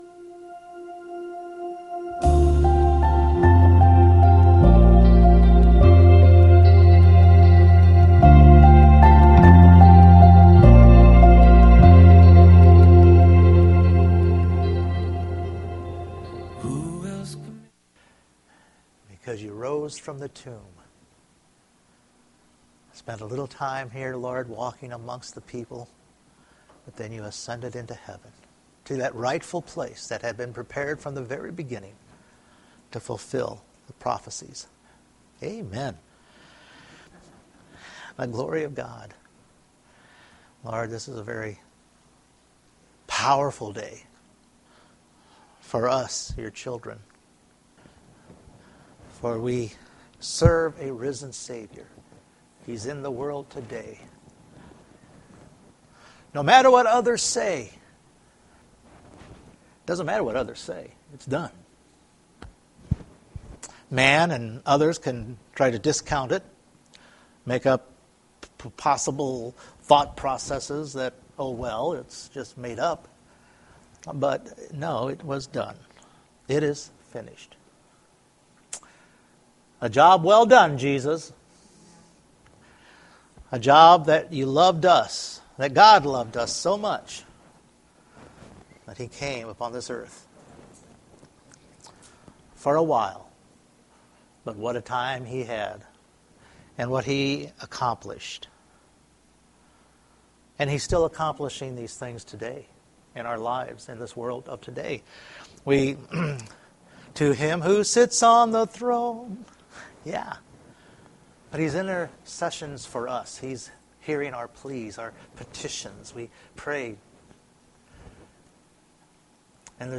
Hebrews 1:1-4 Service Type: Sunday Morning Jesus now sits at the right hand of God for us and our needs.